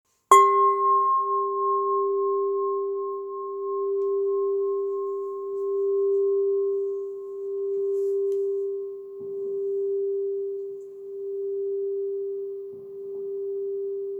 Une expérience sonore profonde et stabilisante
• la vibration est profonde et enveloppante
• les harmoniques se déploient naturellement
Sa sonorité douce en fait un outil particulièrement apprécié pour :
2⃣ Frappez délicatement la partie centrale avec le maillet
Matériau : aluminium acoustique traité thermiquement pour une résonance stable et claire
Fréquence : accordé précisément à 396 Hz
fréquence-396-Hz.mp3